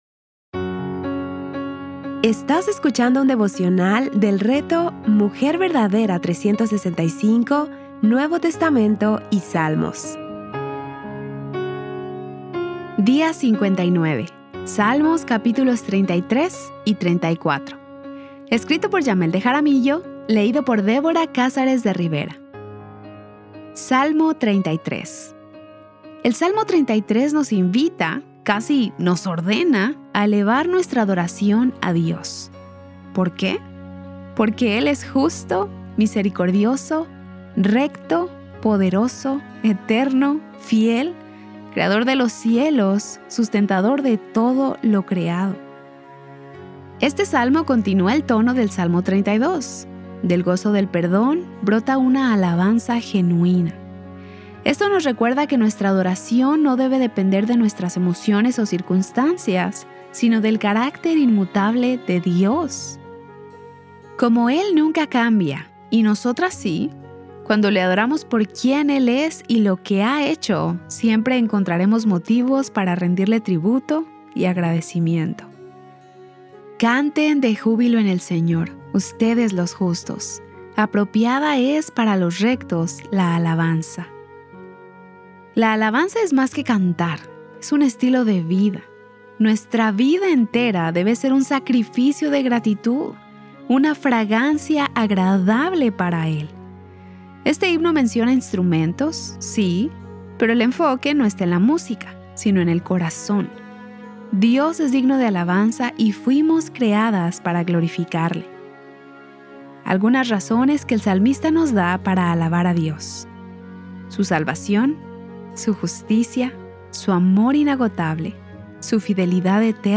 Series:  Marcos y Salmos | Temas: Lectura Bíblica